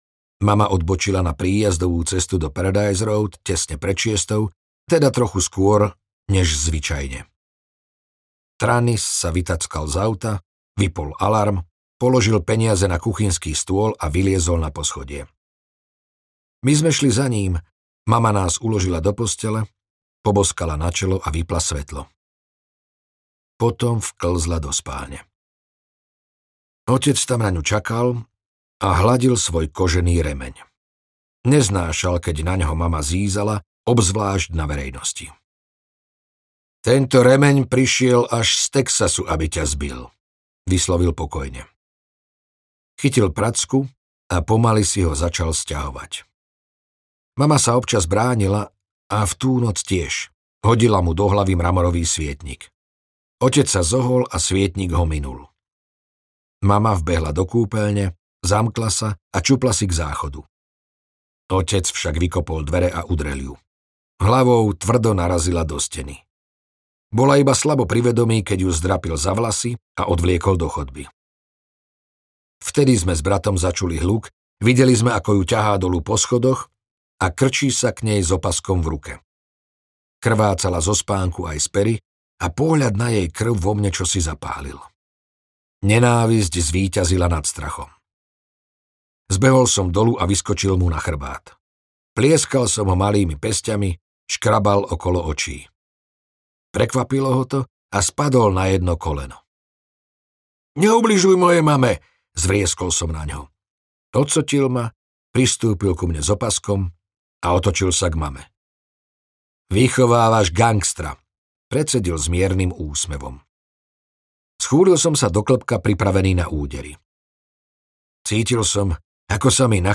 Nič ma nezlomí audiokniha
Ukázka z knihy